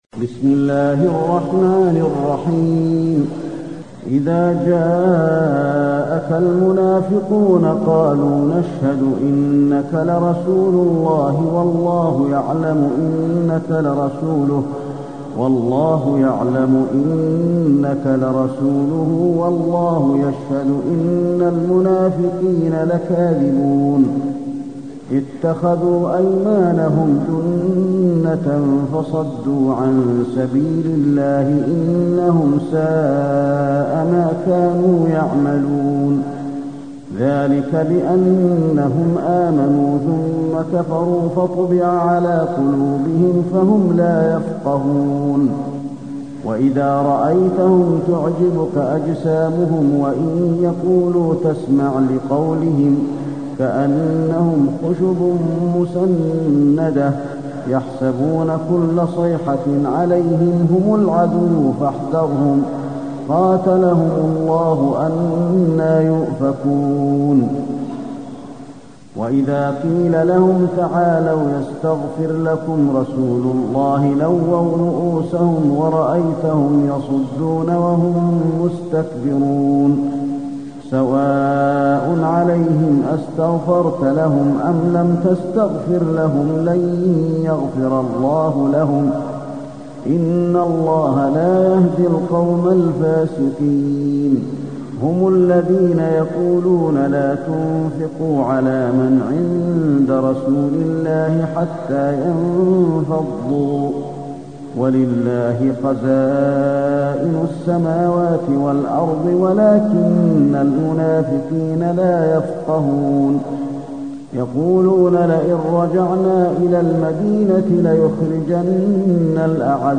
المكان: المسجد النبوي المنافقون